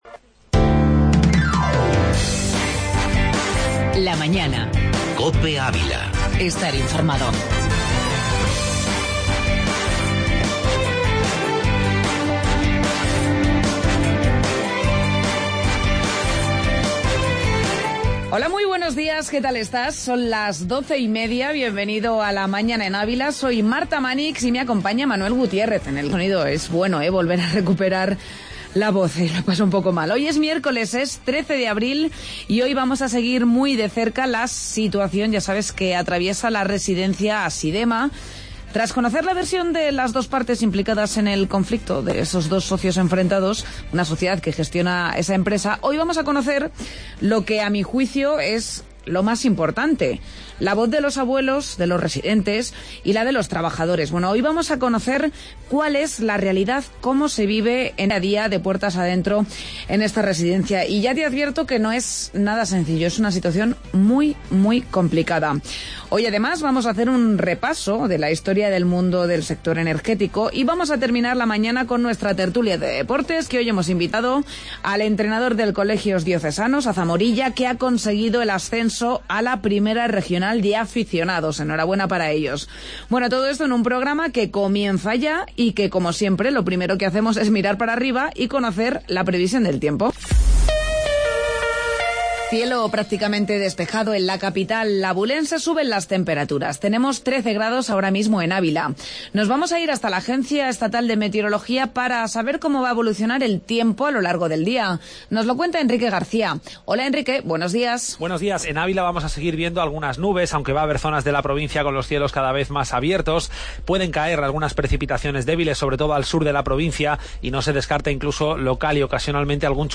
AUDIO: Entrevista Asidema